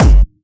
VEC3 Bassdrums Dirty 01.wav